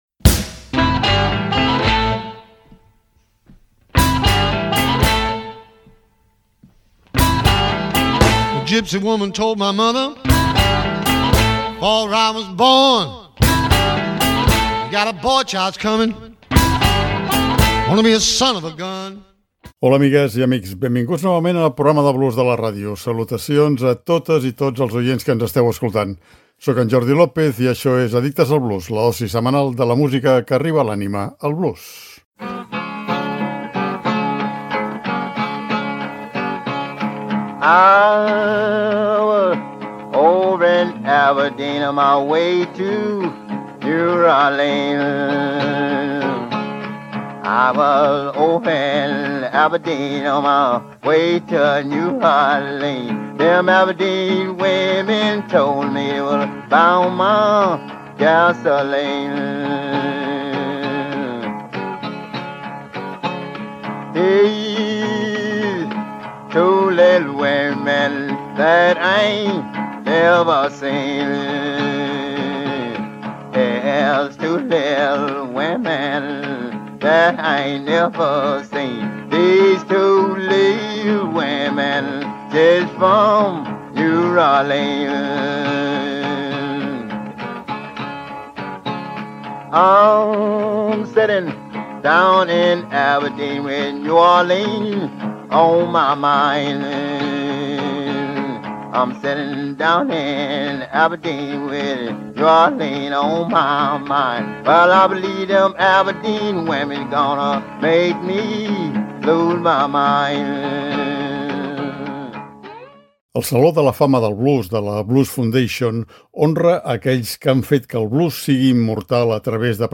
I justament en aquest programa, i en uns quants més, relacionarem i repassarem alguns d’aquests àlbums clàssics que han merescut un lloc en el prestigiós saló, a 45 anys de la seva fundació. Un veritable afalac musical que bé pot funcionar com una llista essencial de discos de blues per a col·lecció.